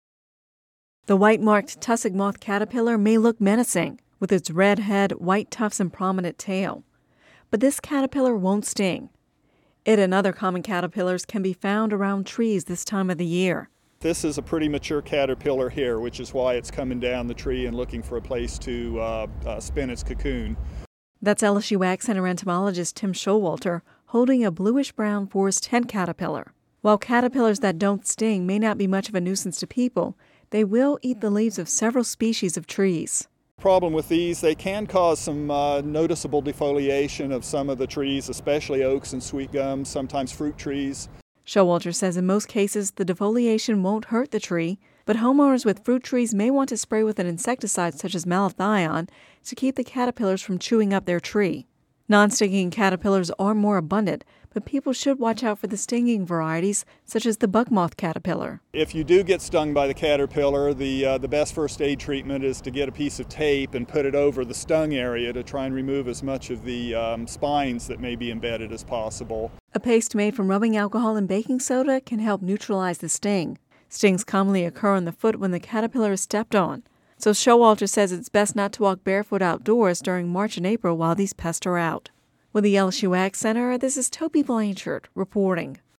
(Radio News 04/14/11) The white-marked tussock moth caterpillar may look menacing, with its red head, white tufts and prominent tail, but this caterpillar won’t sting. It and other common caterpillars can be found around trees this time of the year.